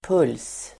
Ladda ner uttalet
puls substantiv, pulse Uttal: [pul:s]